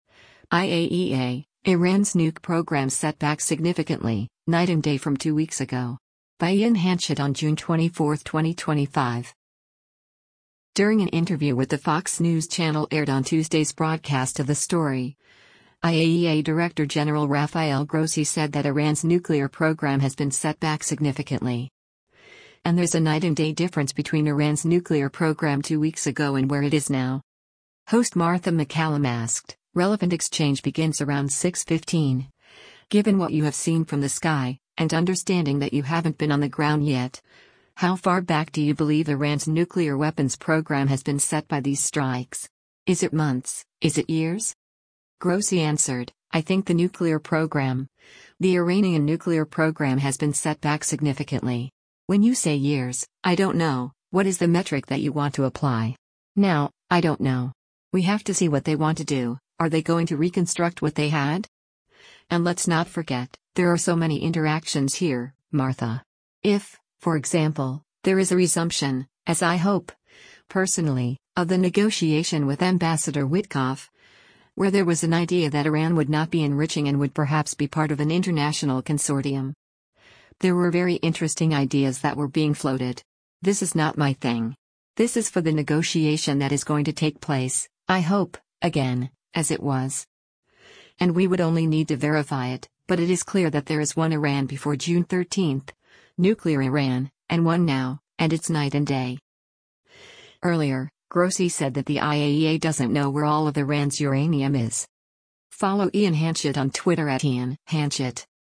During an interview with the Fox News Channel aired on Tuesday’s broadcast of “The Story,” IAEA Director General Rafael Grossi said that Iran’s “nuclear program has been set back significantly.” And there’s a “night and day” difference between Iran’s nuclear program two weeks ago and where it is now.